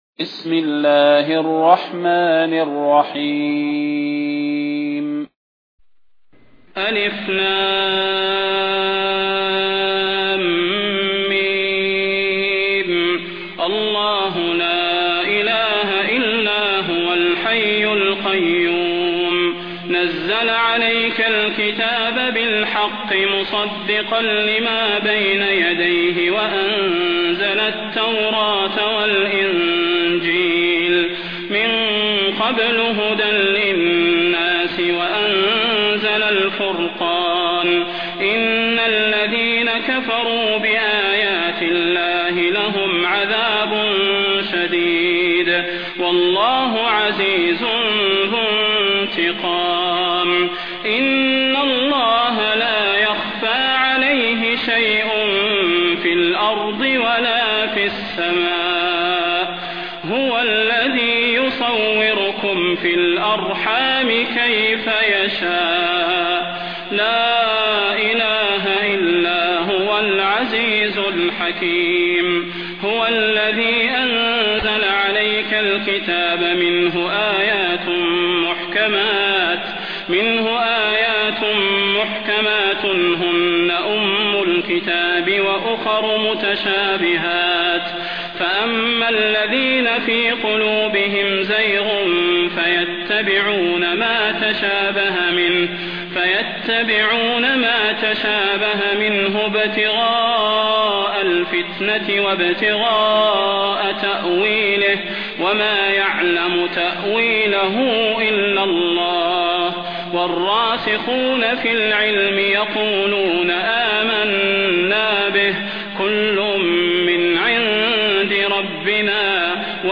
المكان: المسجد النبوي الشيخ: فضيلة الشيخ د. صلاح بن محمد البدير فضيلة الشيخ د. صلاح بن محمد البدير آل عمران